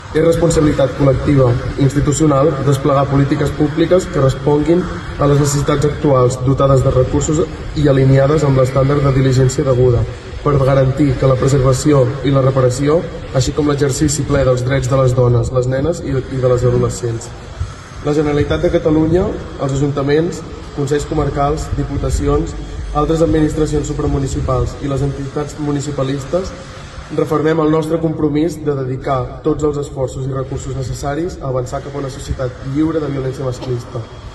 Són declaracions del regidor de l’Ajuntament de Begur, Jordi González.